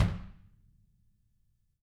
Index of /90_sSampleCDs/ILIO - Double Platinum Drums 1/CD2/Partition A/REMO KICK R